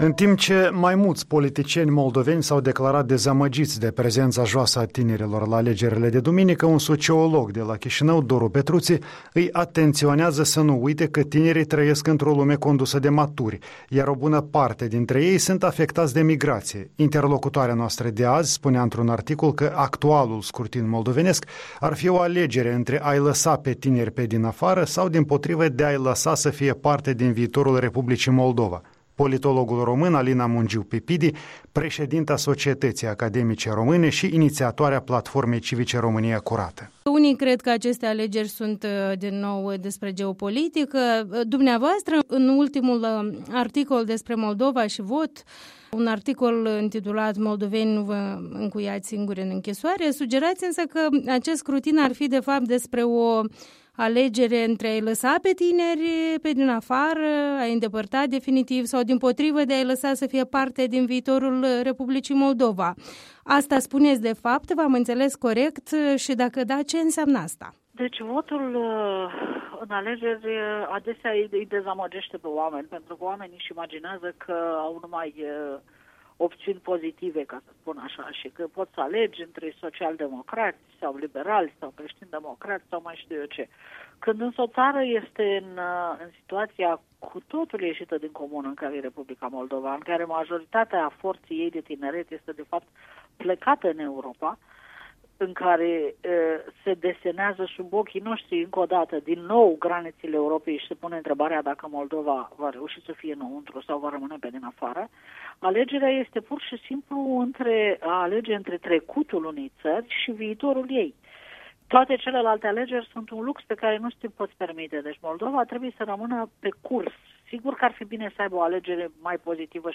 Interviul dimineții cu preşedinta Societăţii Academice Române şi iniţiatoarea Platformei civice „România Curată”, prof. univ. la Berlin.
Interviul dimineții: cu prof. univ.